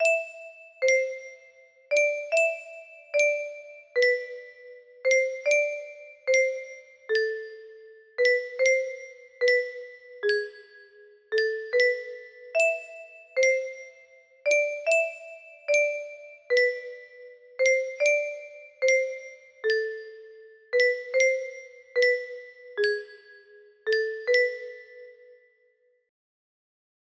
Windup Music music box melody
Full range 60